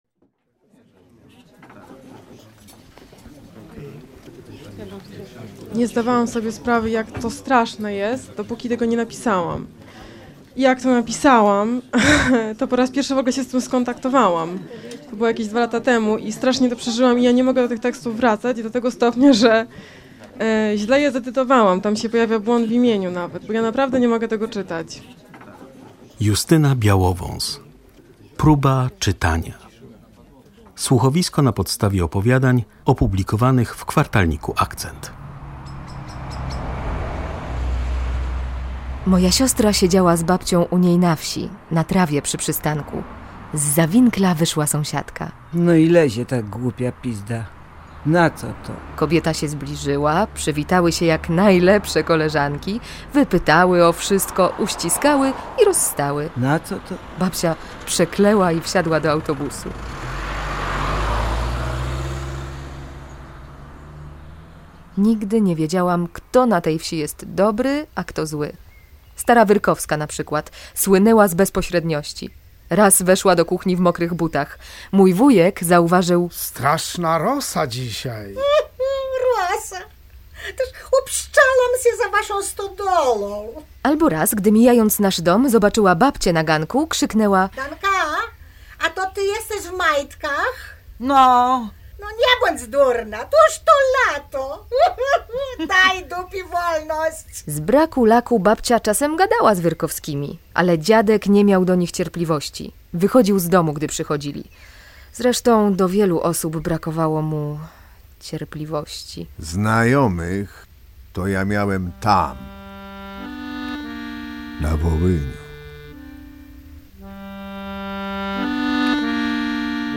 Słuchowisko pt. „Próba czytania", jakie prezentujemy w pierwszej części „Radioteatru" nawiązuje do obchodzonej 11 lipca 80. rocznicy krwawej niedzieli 1943
W tle jego pełnych współczucia dla walczących Ukraińców wierszy znakomita muzyka: improwizacje na perkusji w wykonaniu wirtuoza Cezarego Konrada z wokalizami Krzysztofa Cugowskiego.